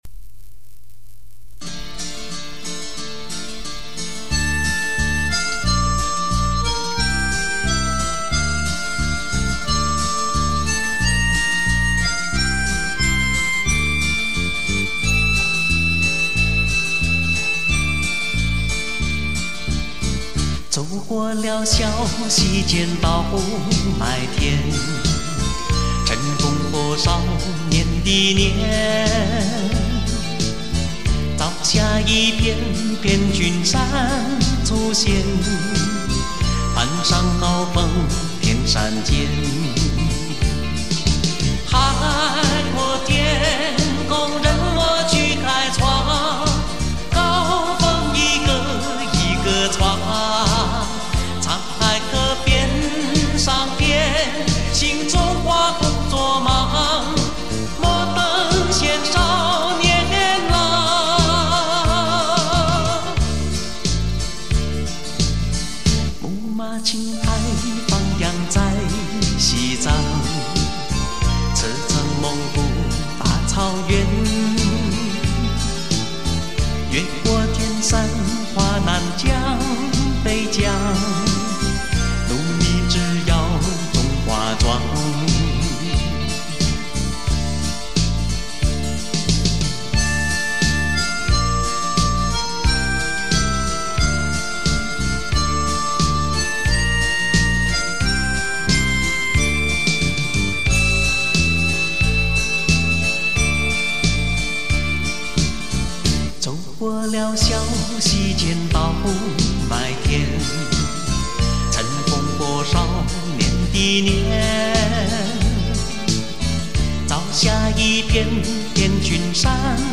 那歌声...那旋律...悠扬飘荡...